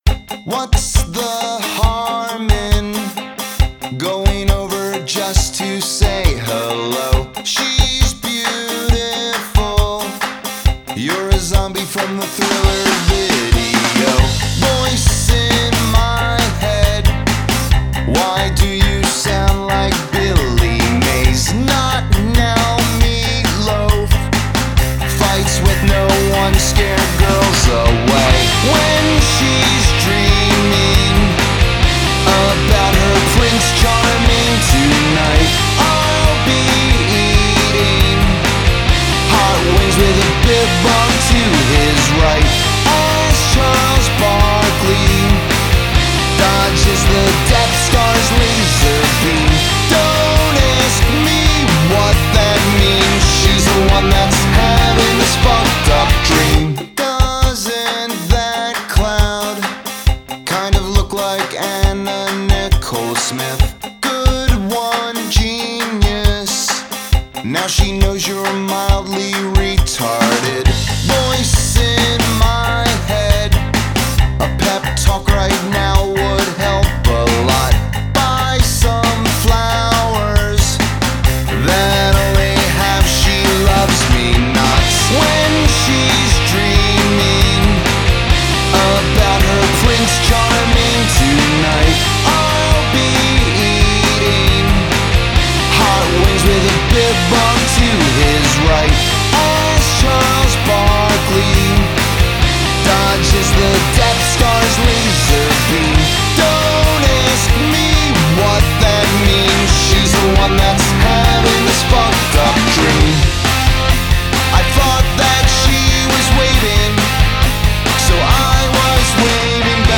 Жанр: Alternative, Rock